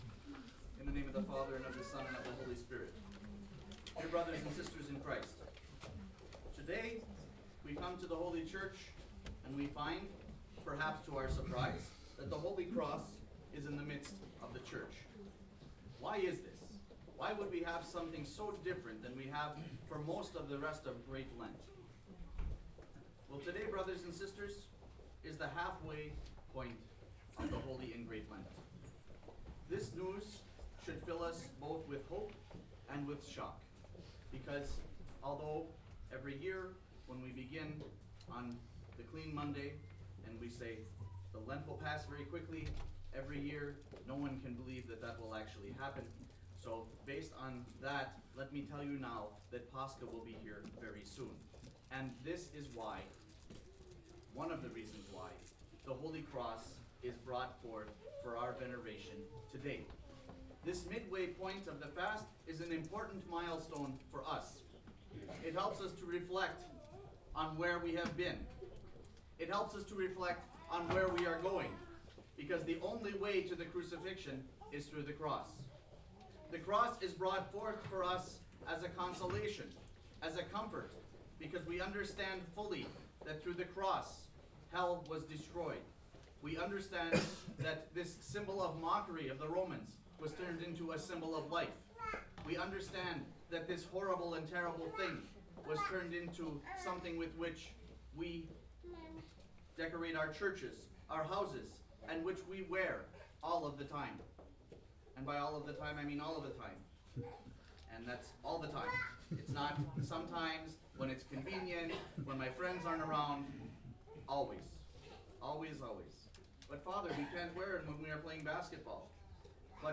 Sermon from March 15, 2015
Sermon Sunday, March 15, 2015